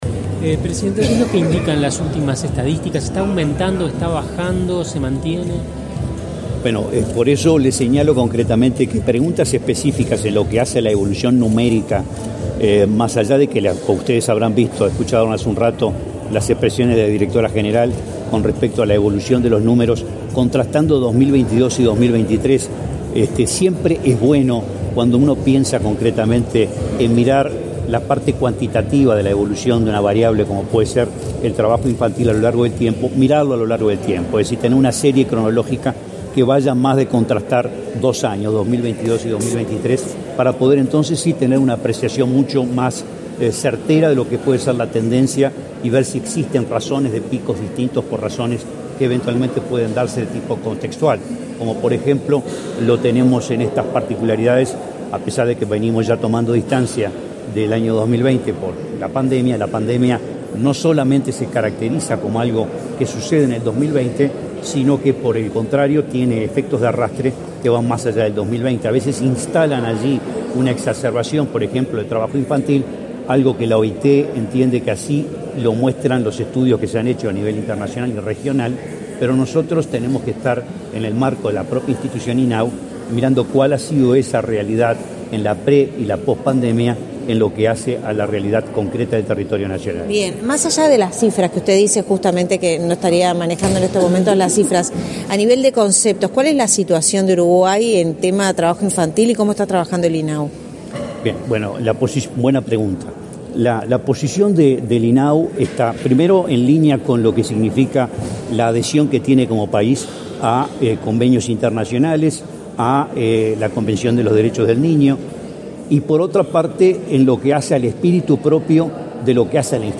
Declaraciones a la prensa del presidente del INAU, Guillermo Fossatti
Tras participar en un acto por el Día Mundial contra el Trabajo Infantil, este 12 de junio, el presidente del Instituto del Niño y el Adolescente del